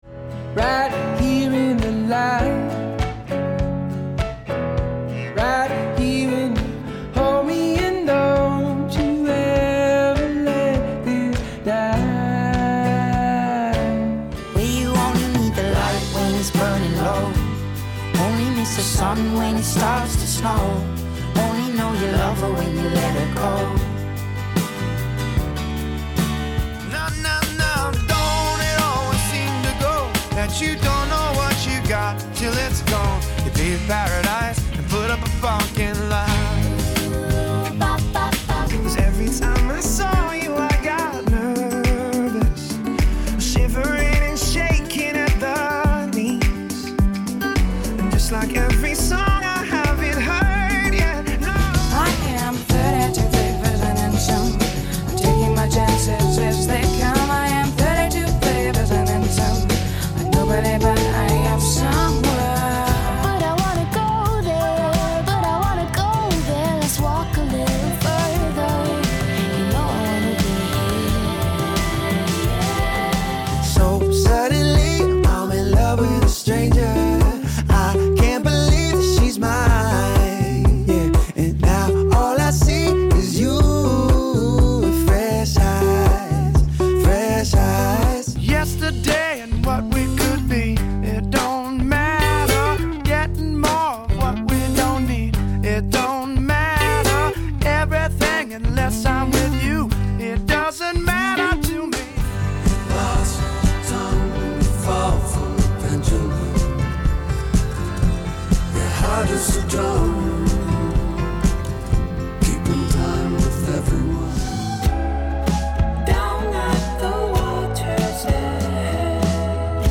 Mixed Tempo